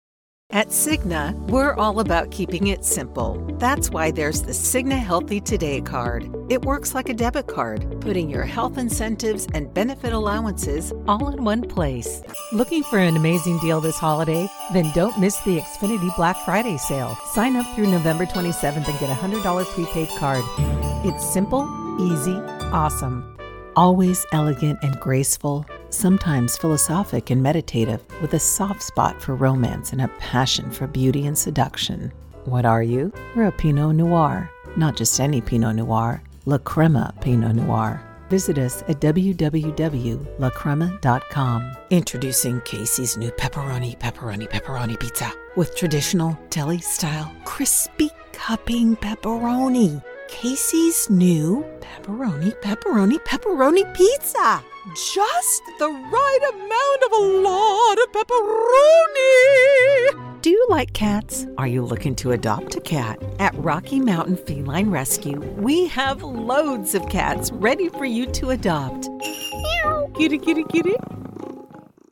Adult
southern us | natural
COMMERCIAL 💸